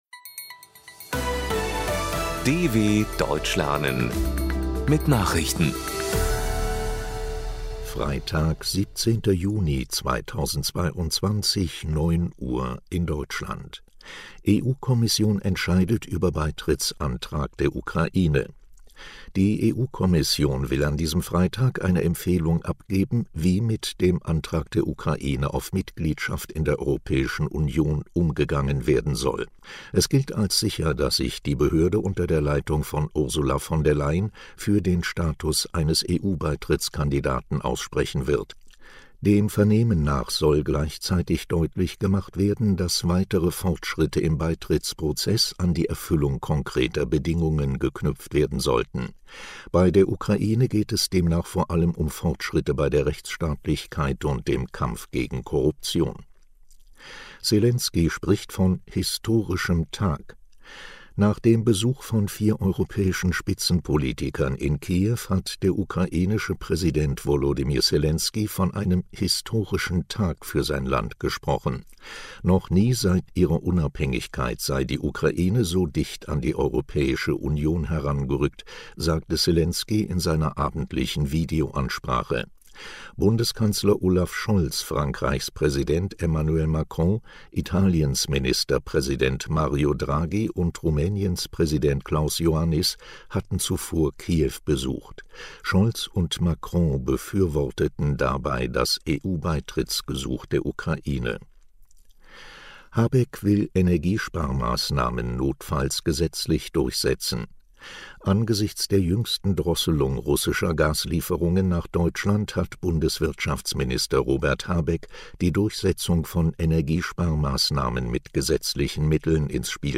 17.06.2022 – Langsam gesprochene Nachrichten
Trainiere dein Hörverstehen mit den Nachrichten der Deutschen Welle von Freitag – als Text und als verständlich gesprochene Audio-Datei.